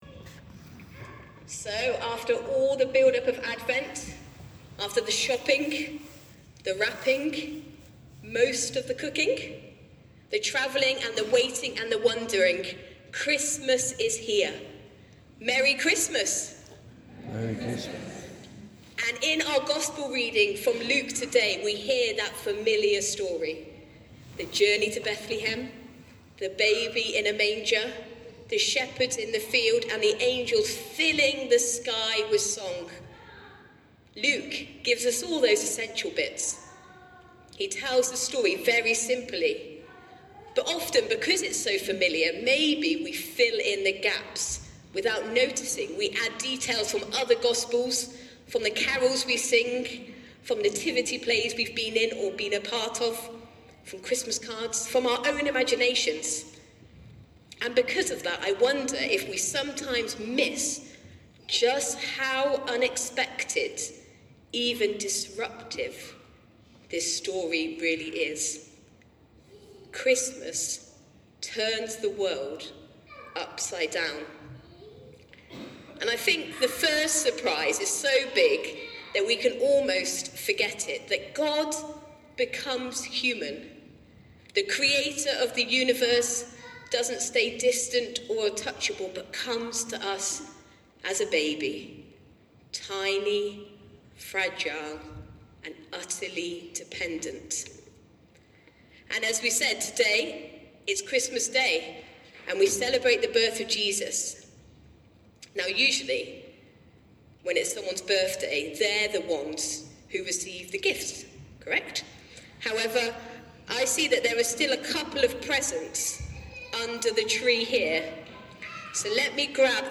Sermon for Christmas Day 25th December 2025